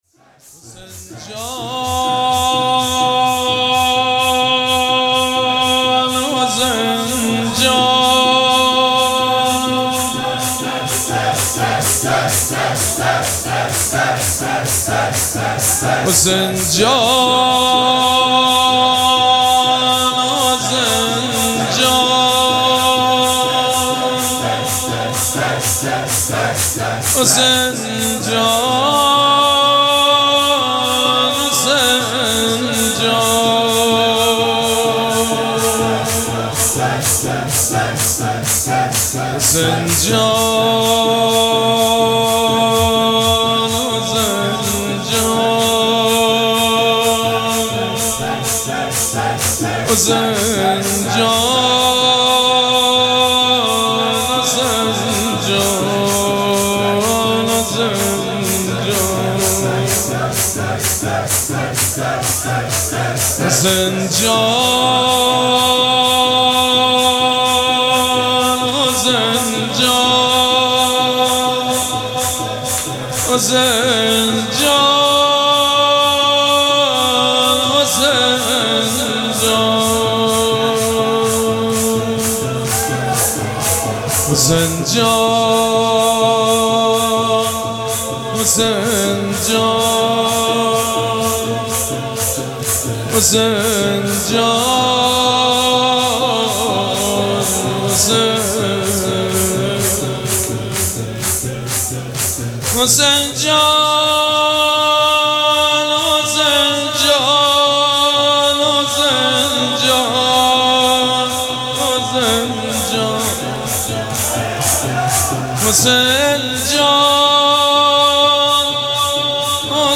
مراسم عزاداری شام شهادت حضرت رقیه سلام الله علیها
شور
مداح